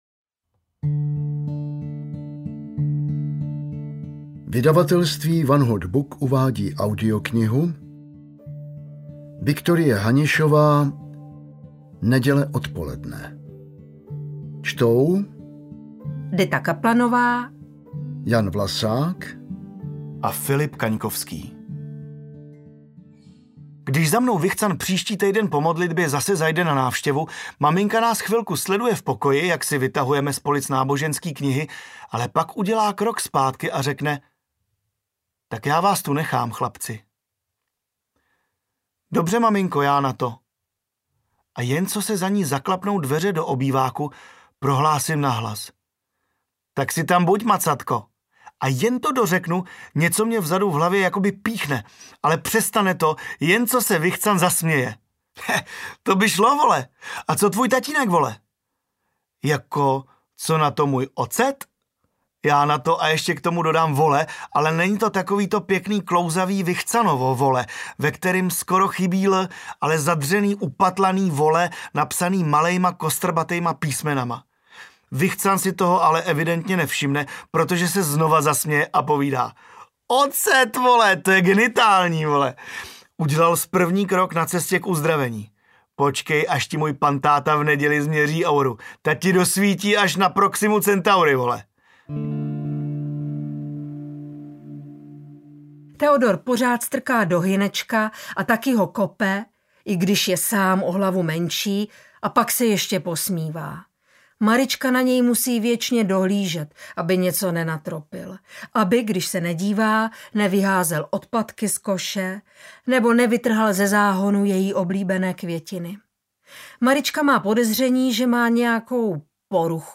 Neděle odpoledne audiokniha
Ukázka z knihy